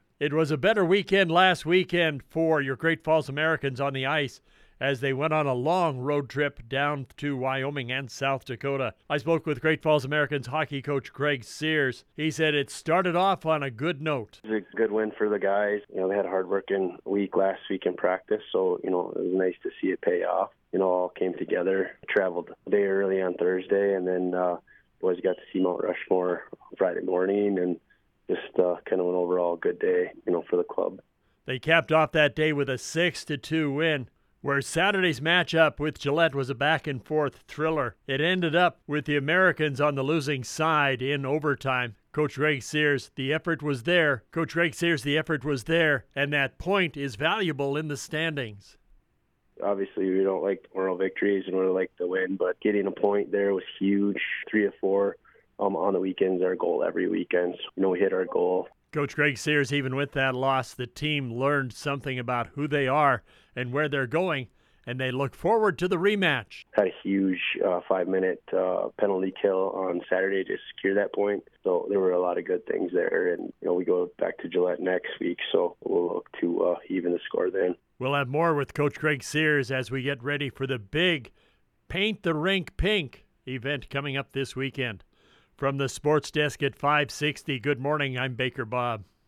The Weekly Radio Interview on 560AM KMON in Great Falls can be heard every Wednesday, Thursday and Friday mornings during the Hourly Sports Report (6:15AM (MST), 7:15AM (MST), and 8:15AM (MST) live.